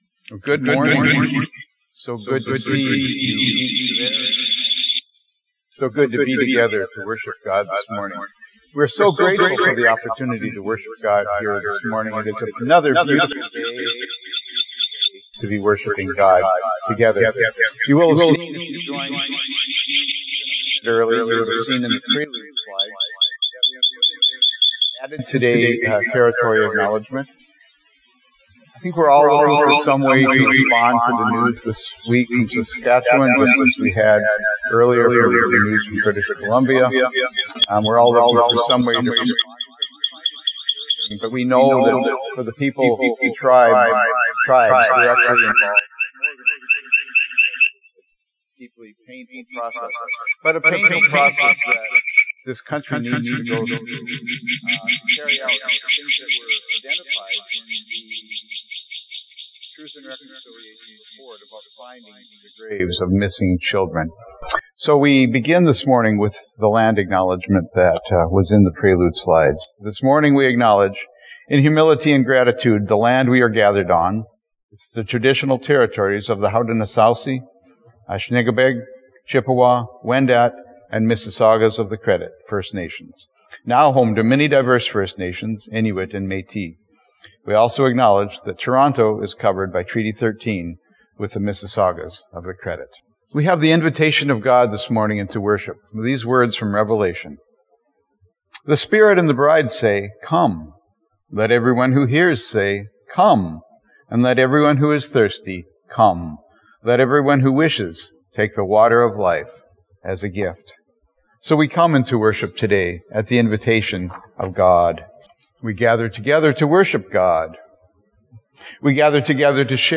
Fellowship Church is pleased to offer this live service at 10AM.
(The Lord's Supper will not be a part of the recorded worship.)